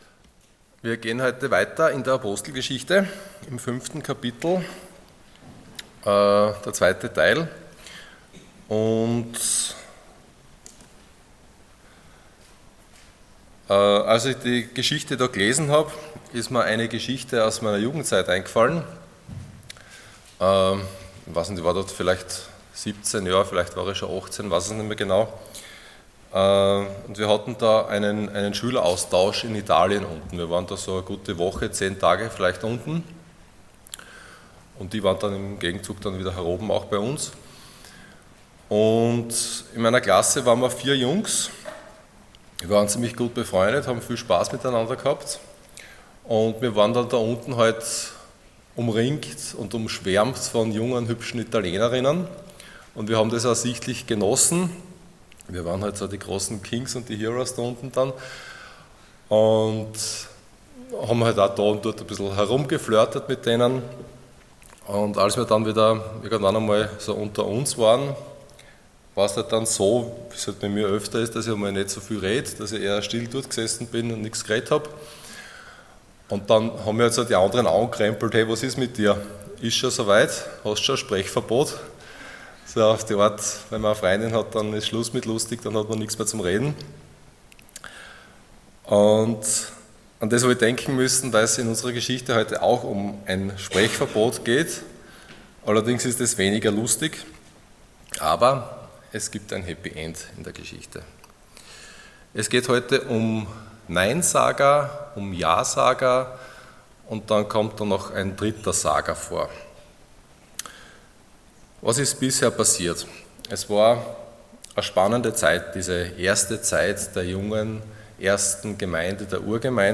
Ihr werdet meine Zeugen sein Passage: Acts 5:17-42 Dienstart: Sonntag Morgen %todo_render% JA